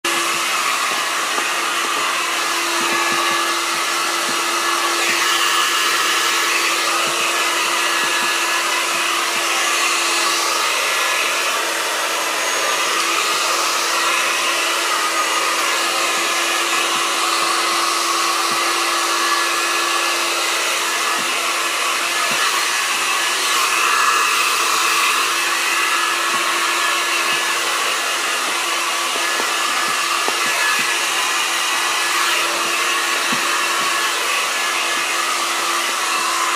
hair-blow-dryer-mp3.mp3